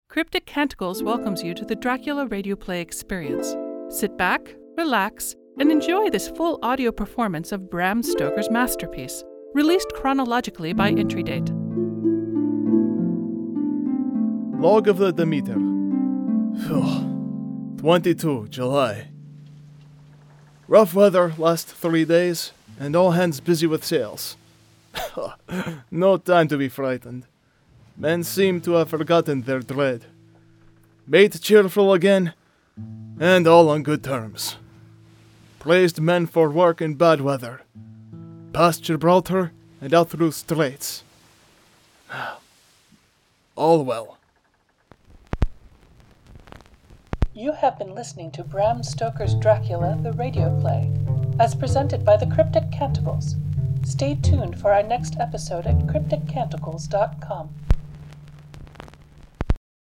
Captain of the Demeter
Audio Engineer, SFX and Music